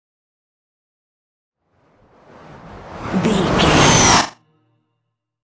Trailer dramatic raiser
Sound Effects
Epic / Action
Fast paced
In-crescendo
Thriller
Atonal
intense
tension
riser